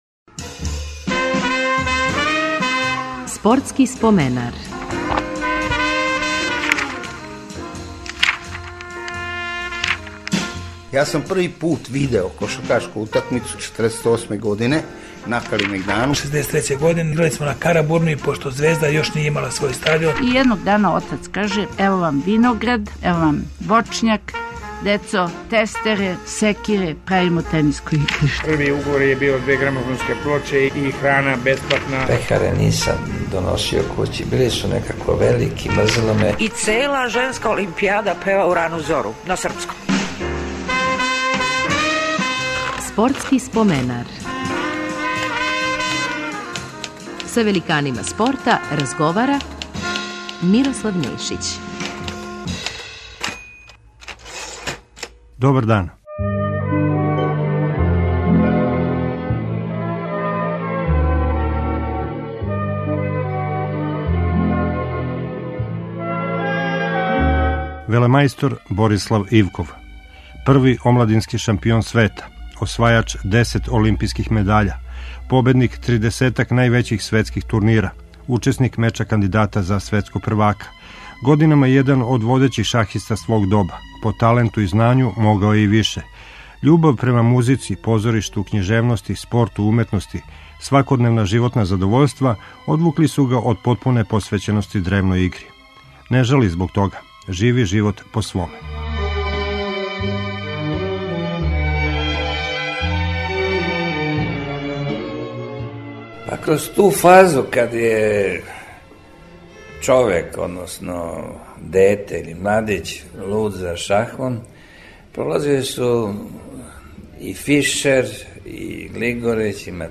Репризираћемо разговор са шахистом Бориславом Ивковим.